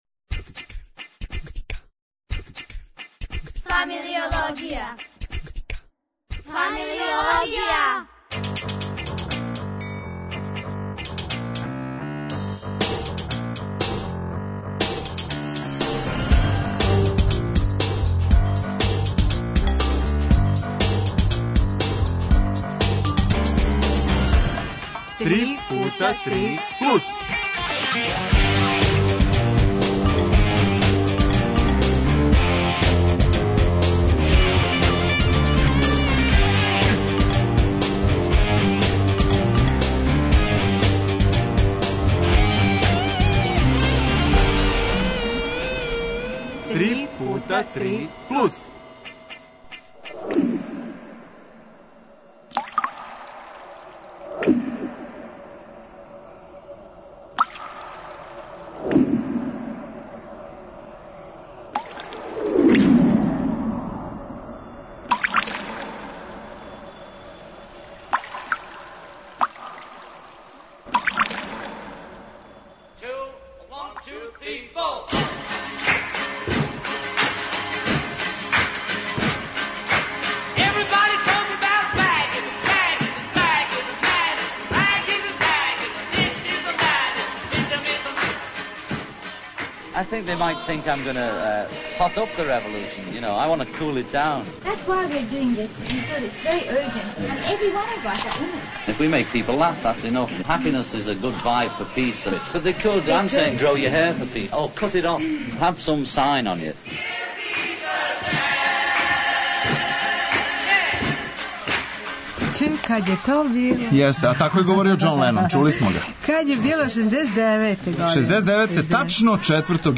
Тим поводом, гости у студију су деца рагбисти РК "Рад". А осим о рагбију, још по која реч и о занимљивостима из Рија, о америчком фудбалу, о освети.